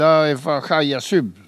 Locution